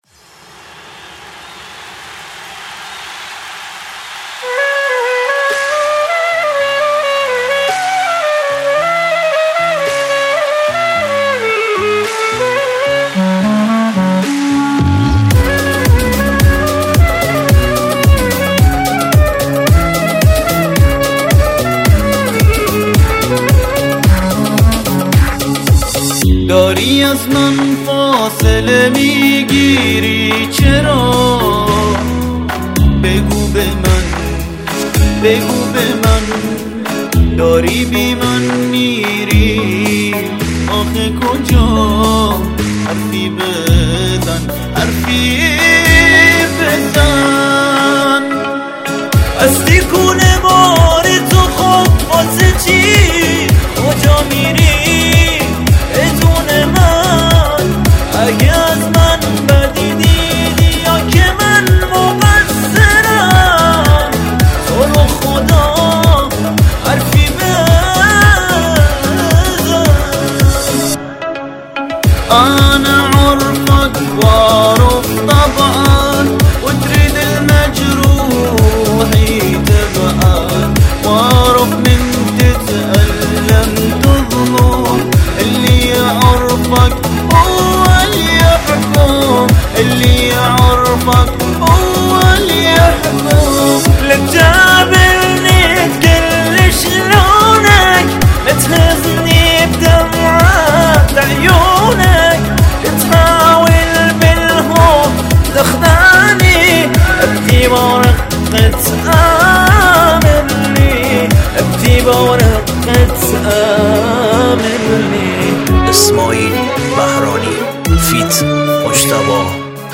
دسته بندي : تک آهنگ ,
این اهنگ به دو زبان فارسی و عربی اجرا شده